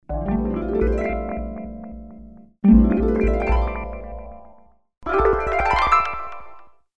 magic-bouncing-harp-spell_fktJ4BN_.mp3